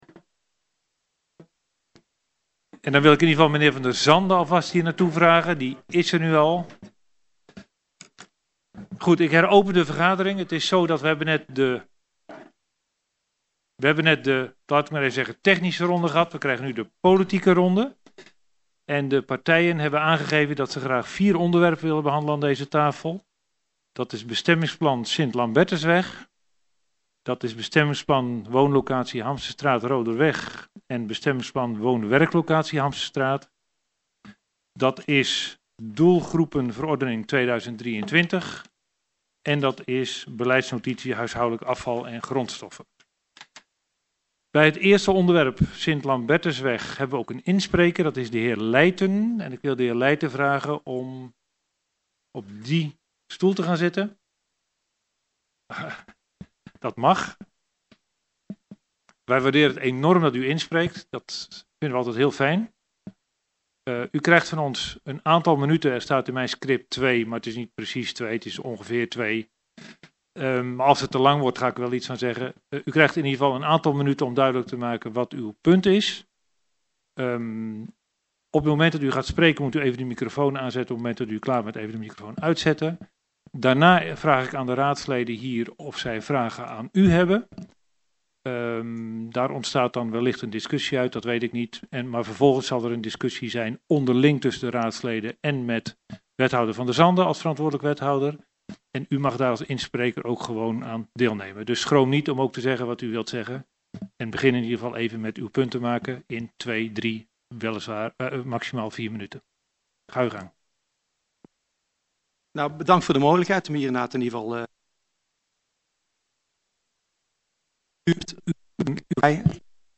Geluidsopname In gesprek onderdeel 3 - Gesprekstafel